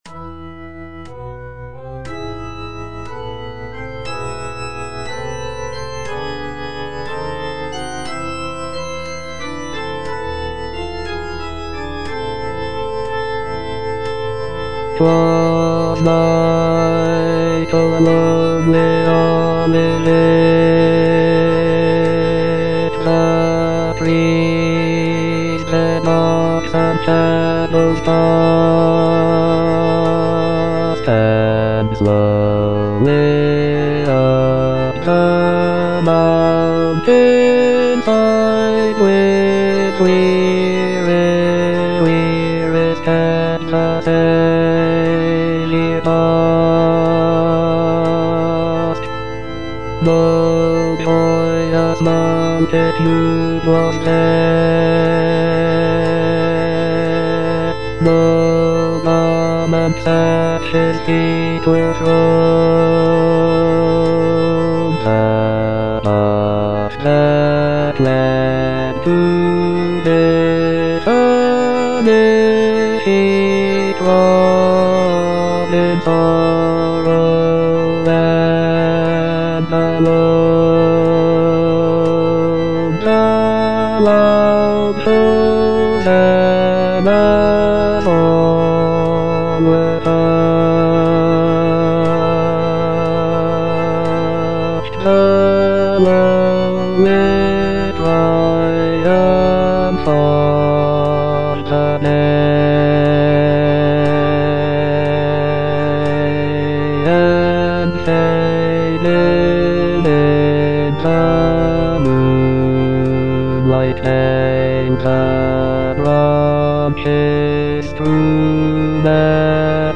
J.H. MAUNDER - OLIVET TO CALVARY 4a. The Mount of Olives (bass I) (Voice with metronome) Ads stop: auto-stop
"Olivet to Calvary" is a sacred cantata composed by John Henry Maunder in 1904.